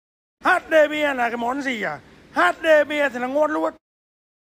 Thể loại: Câu nói Viral Việt Nam
Description: Âm thanh meme HDPE thì ngon luôn Bản gốc là đoạn sound effect được tách ra từ video TikTok viral nơi người đàn ông trung niên trả lời câu hỏi về đầu tư bằng câu nói HDPE thì ngon luôn rồi được cộng đồng mạng dùng làm hiệu ứng âm thanh hài hước khi edit video trend, meme hoặc bình luận vui trong tình huống khác nhau...